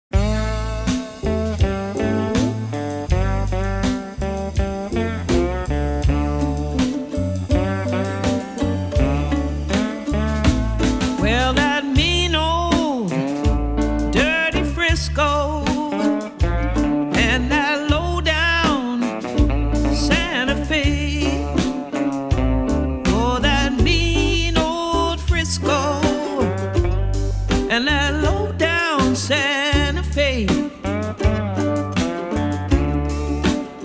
CONCERT HIGHLIGHTS!!